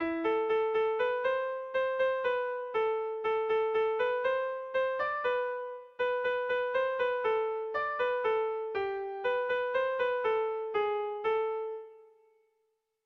Bertso melodies - View details   To know more about this section
Tragikoa
AB